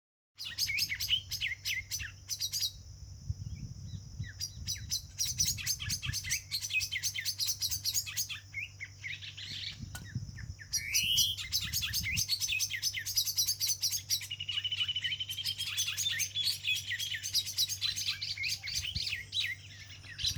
Bluish-grey Saltator (Saltator coerulescens)
Condition: Wild
Certainty: Observed, Recorded vocal